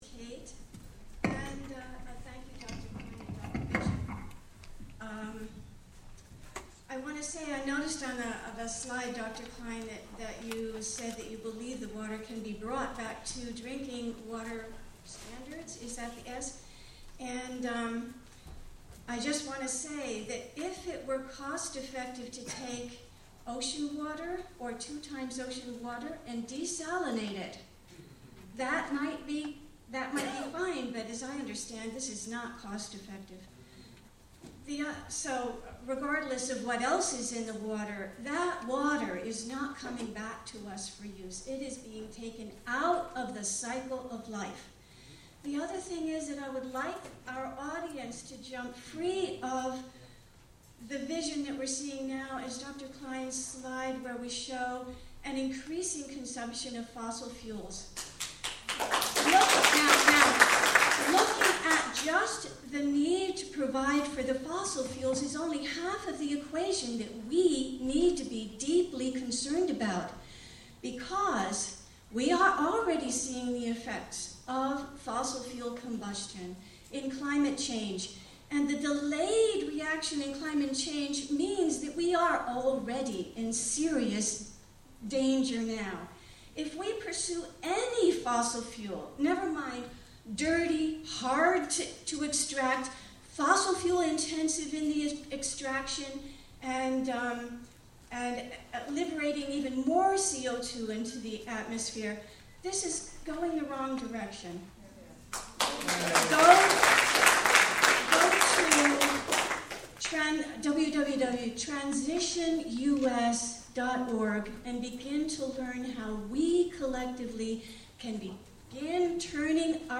Questions from audience after debate on natural gas hydraulic fracturing.